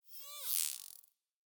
Minecraft Version Minecraft Version snapshot Latest Release | Latest Snapshot snapshot / assets / minecraft / sounds / mob / dolphin / idle_water9.ogg Compare With Compare With Latest Release | Latest Snapshot
idle_water9.ogg